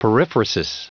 Prononciation du mot periphrasis en anglais (fichier audio)
Prononciation du mot : periphrasis